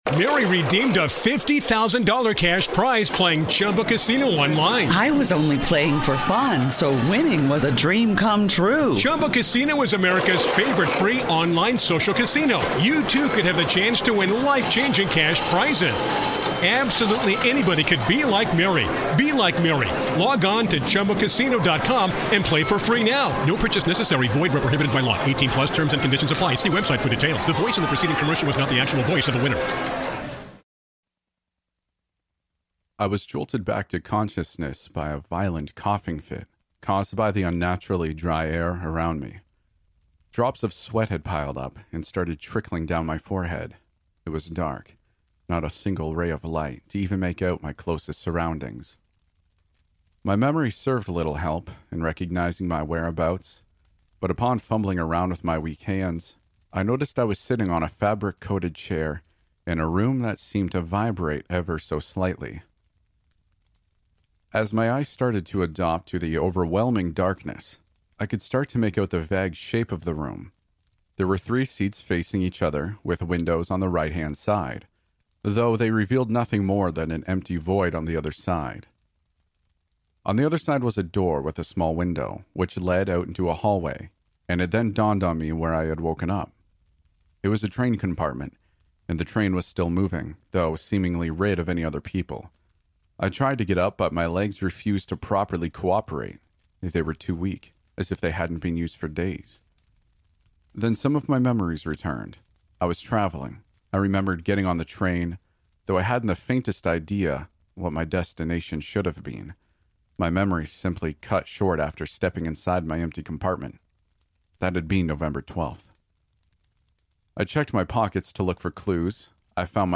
Episode 21 | The train I'm on hasn't stopped driving for 17 days | Storytelling